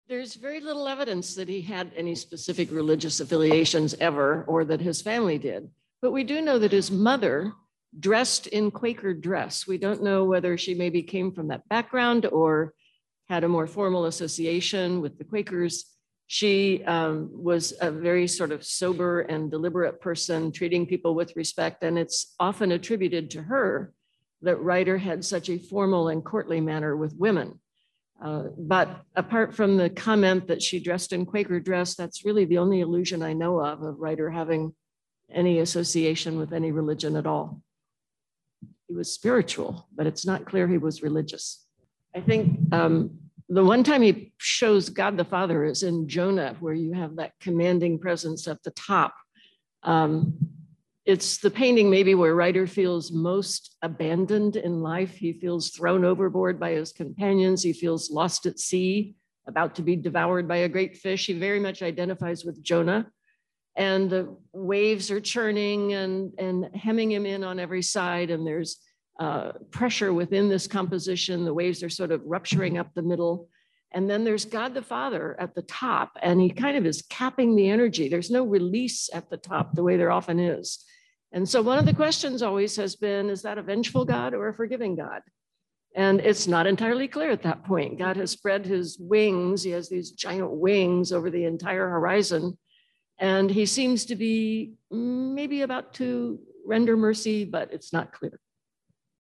Discussion with the Curators